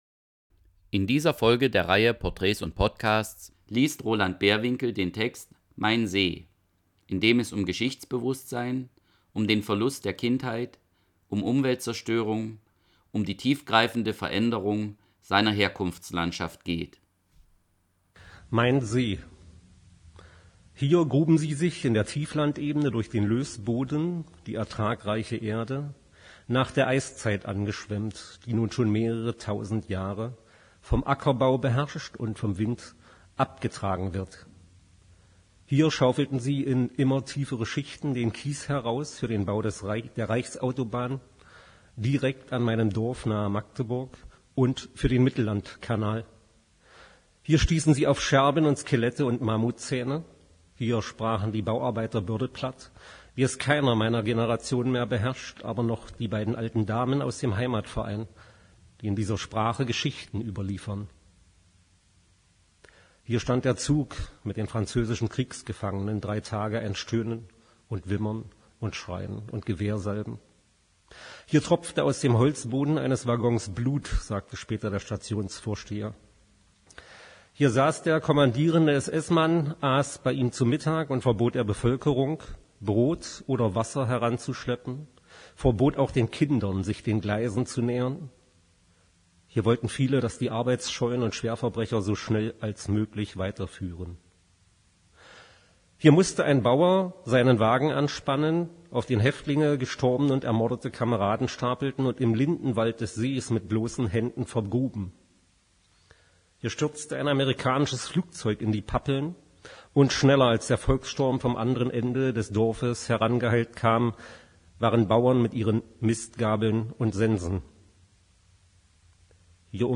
Schriftsteller lesen